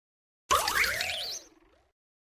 Cri d'Ogerpon dans Pokémon Écarlate et Violet.
Cri_1017_EV.ogg